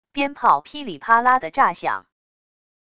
鞭炮噼里啪啦的炸响wav音频